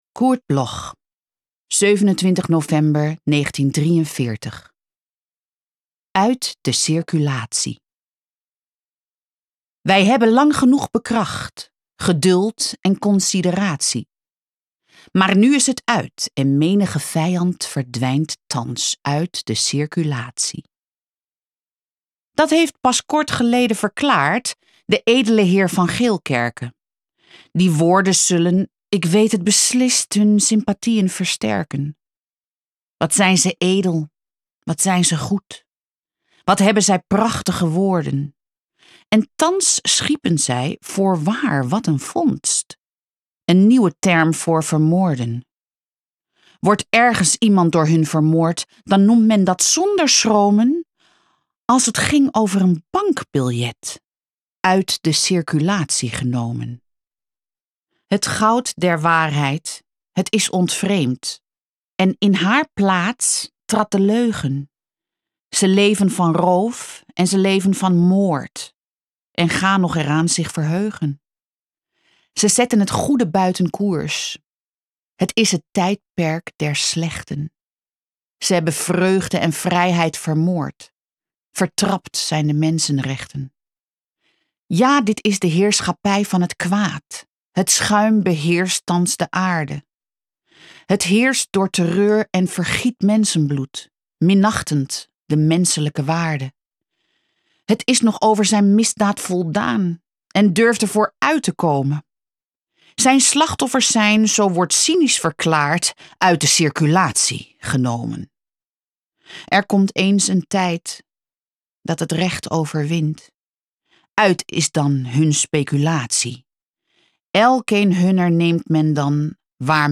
Aufnahme: Speak Low, Berlin · Bearbeitung: Kristen & Schmidt, Wiesbaden